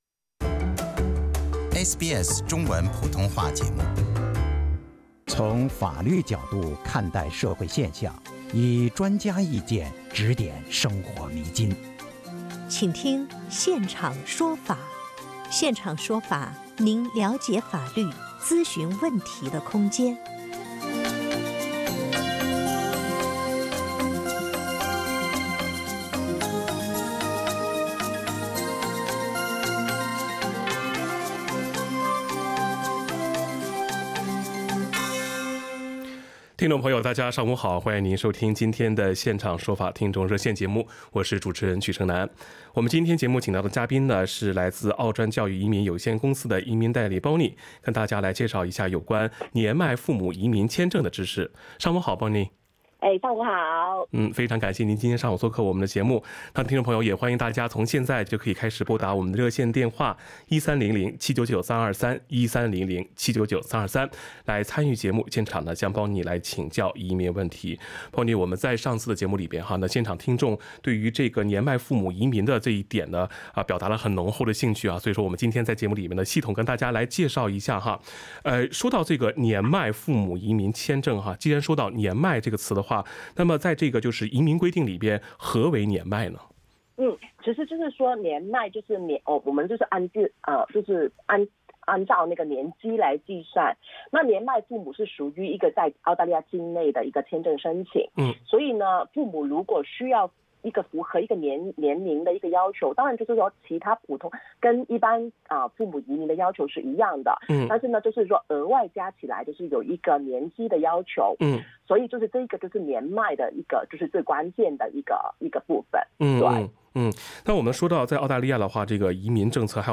本期《現場說法》聽眾熱線話題：“年邁父母移民籤證”。